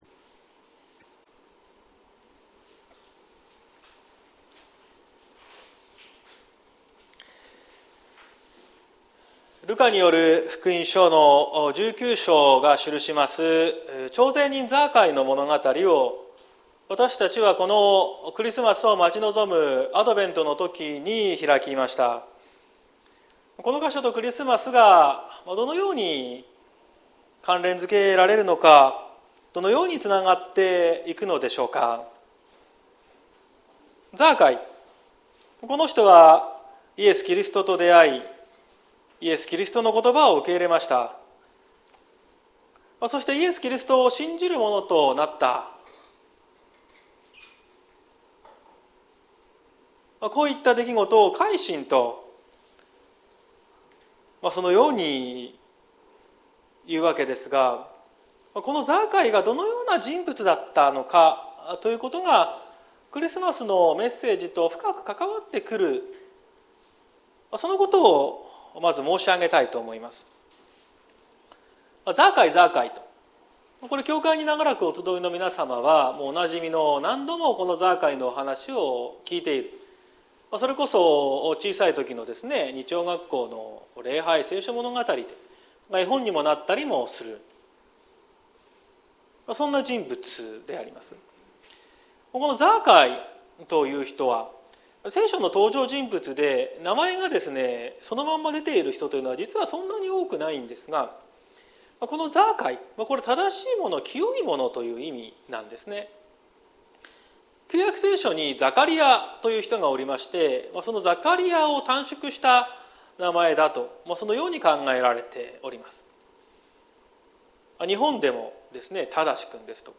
2022年12月11日朝の礼拝「キリストがやってくる」綱島教会
綱島教会。説教アーカイブ。